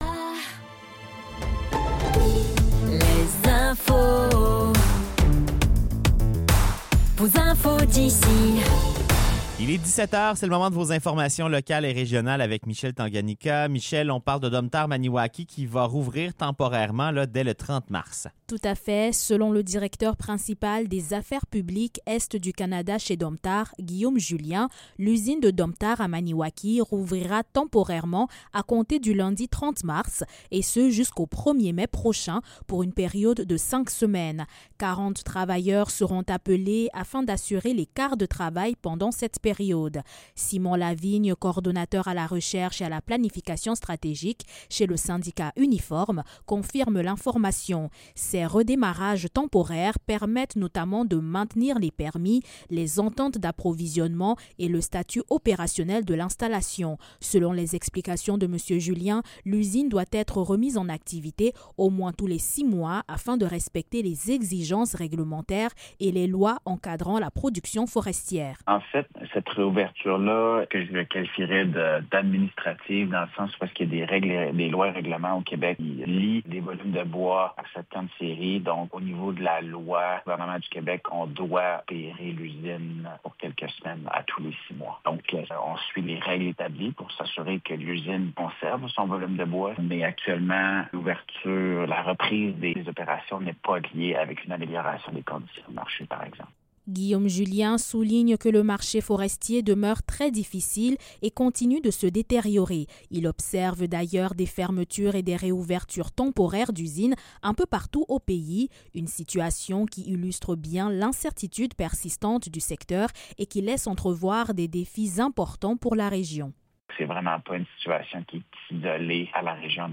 Nouvelles locales - 23 mars 2026 - 17 h